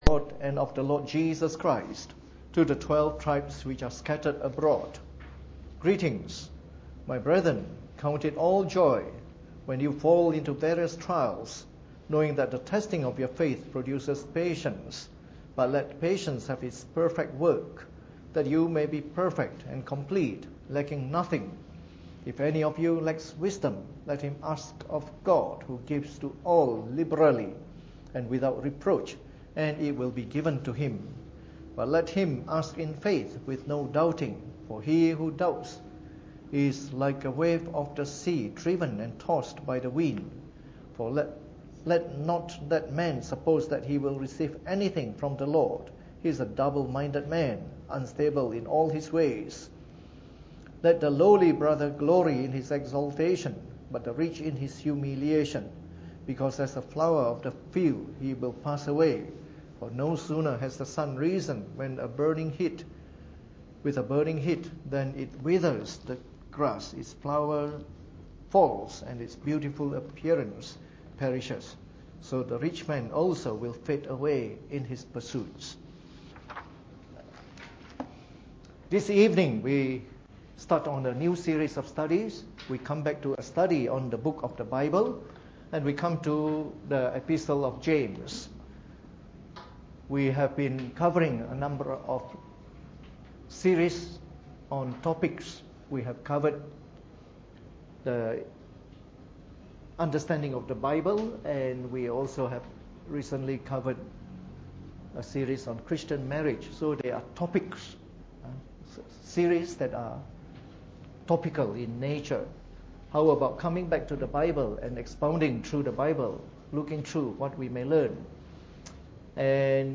Preached on the 23rd of September 2015 during the Bible Study, from our new series on the Epistle of James.